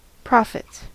Ääntäminen
Ääntäminen US Haettu sana löytyi näillä lähdekielillä: englanti Käännöksiä ei löytynyt valitulle kohdekielelle. Profits on sanan profit monikko.